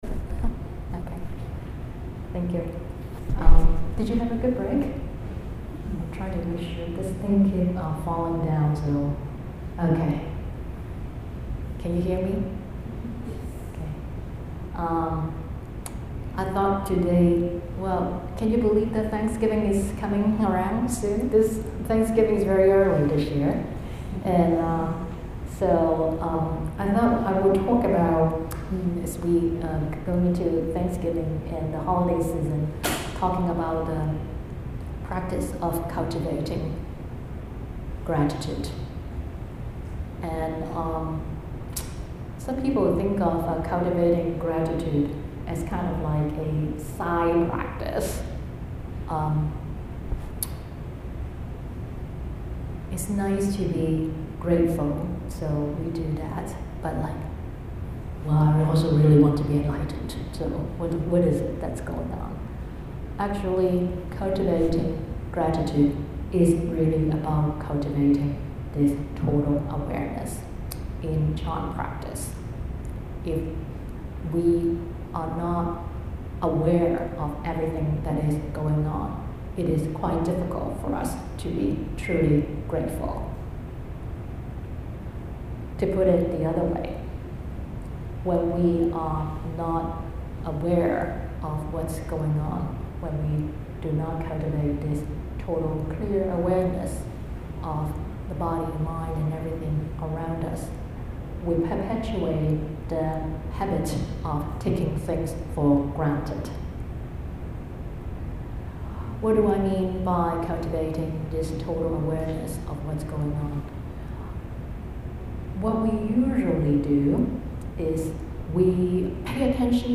This talk was given at the Sunday meditation workshop at the New Jersey branch of Dharma Drum Mountain Buddhist Association on November 11, 2018.